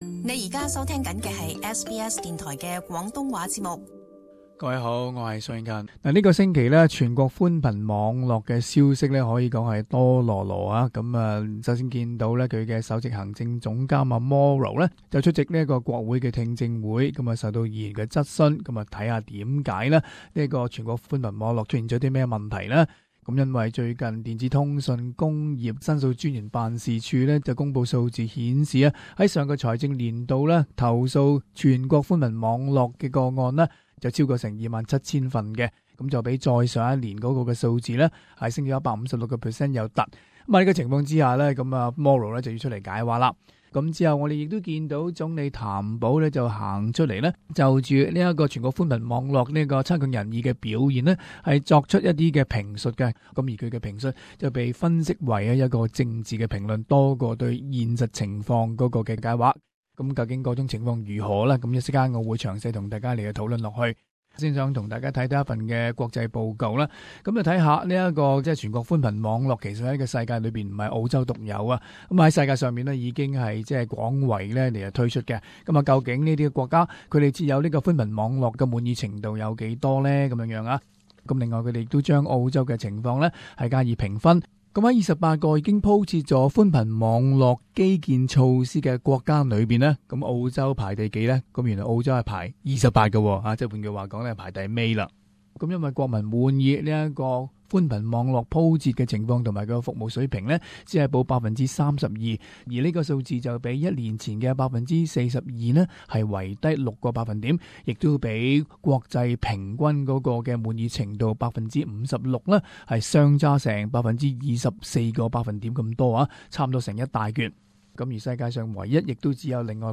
【時事報導】全國寬頻網絡出現了什麽問題？